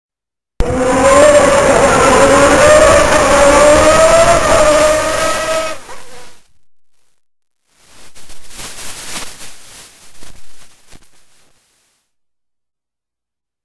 IWAYA ENGINE SOUND COLLECTION